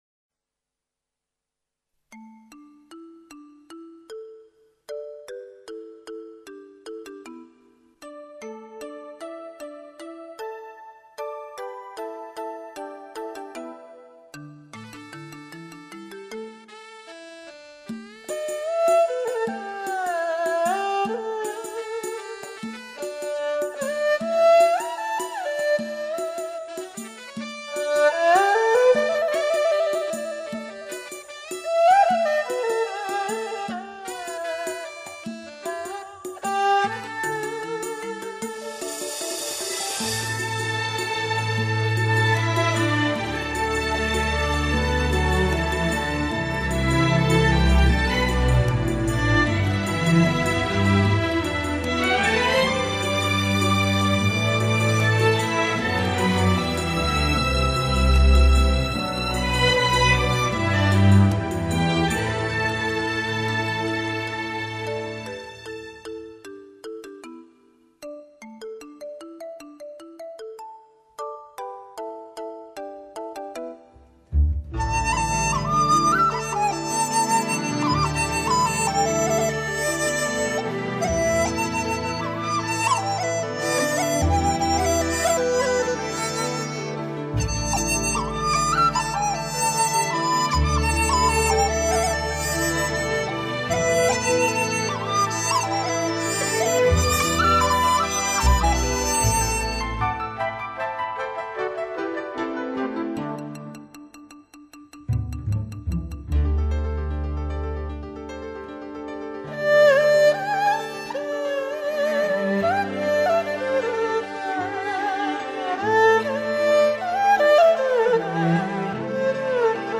【所属类别】音乐 国乐/民族
本辑是以山西民歌为主题改编的轻音乐专集。
最新数码系统录制，发烧品质，优美感人。